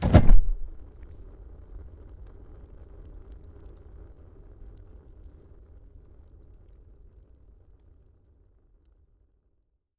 Index of /sound_effects_and_sound_reinforcement/The_Passion_of_Dracula/sounds
D12_85c_whoosh.wav